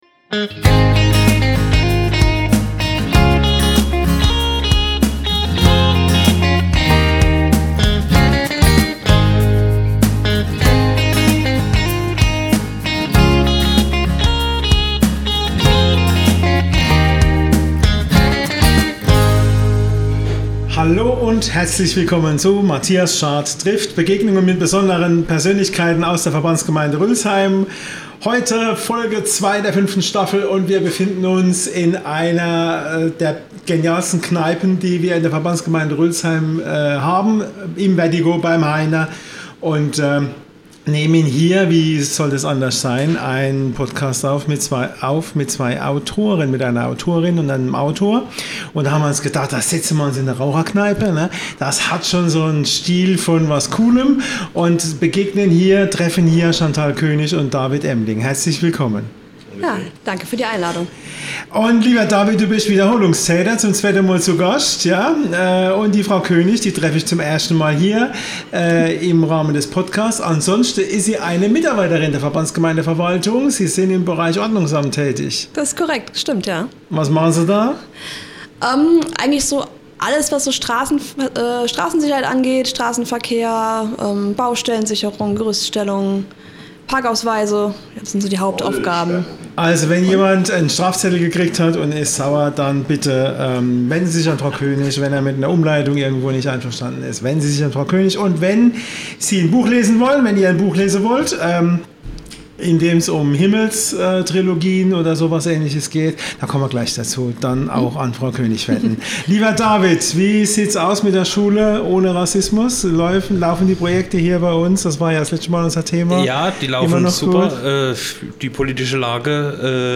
Und wo könnte man sich besser über das Thema unterhalten als in Rülzheims Kult-Kneipe, dem "Vertiko"? Dort sprechen die drei über Inspiration, Schreibtechniken, den Weg vom Manuskript zum Buch und vieles mehr.